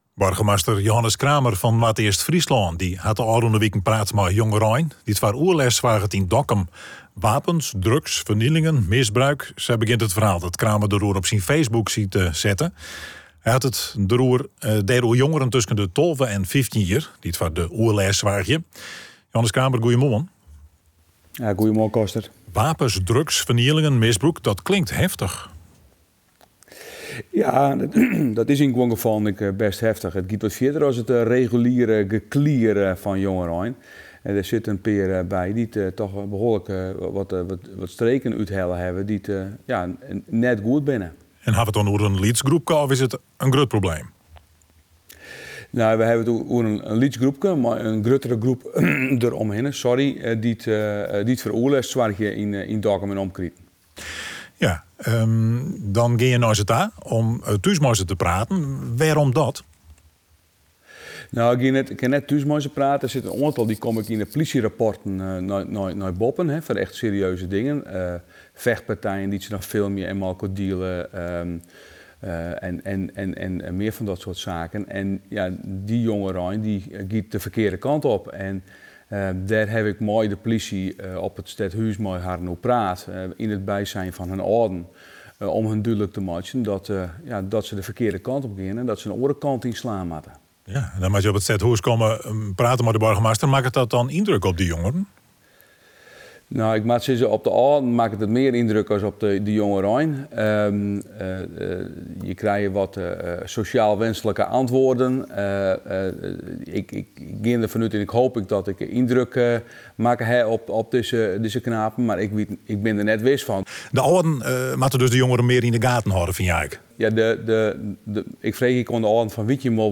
Burgemeester Johannes Kramer in gesprek met presentator